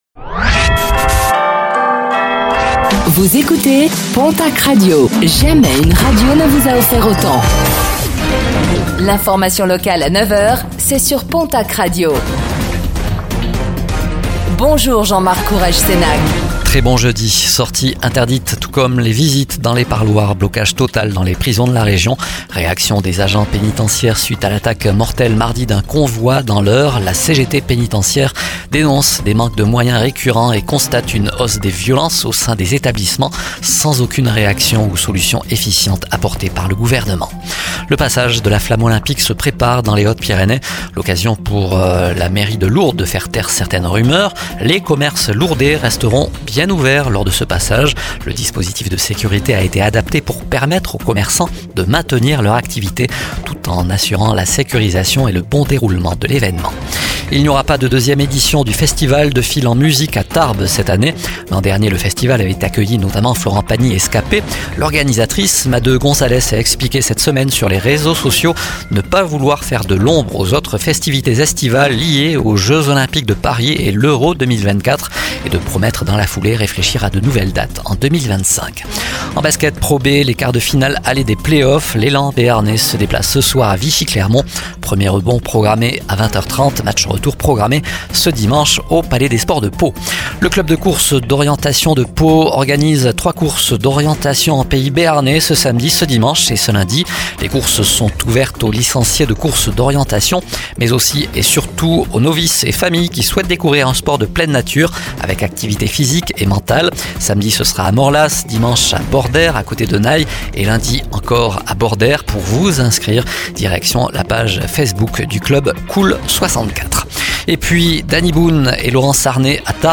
Réécoutez le flash d'information locale de ce jeudi 16 mai 2024